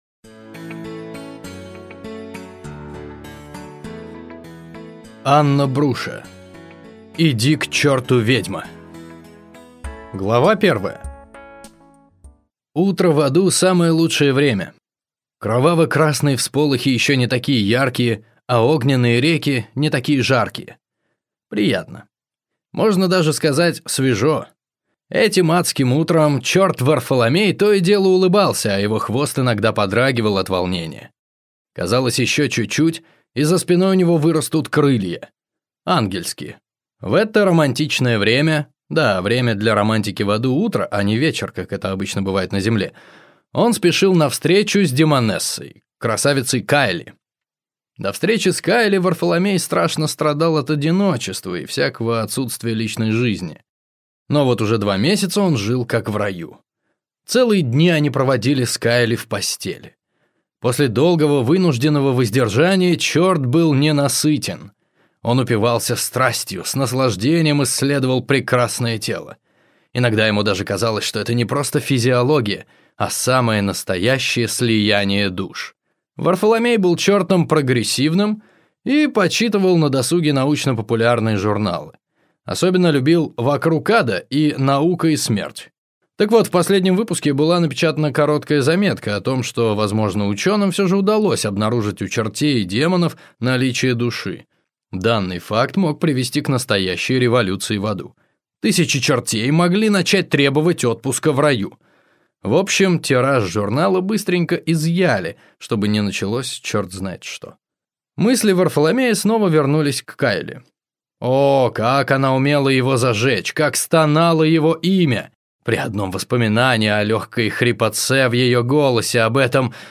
Aудиокнига Иди к черту, ведьма!